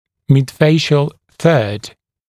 [mɪd’feɪʃl θɜːd][мид’фэйшл сё:д]средняя треть лица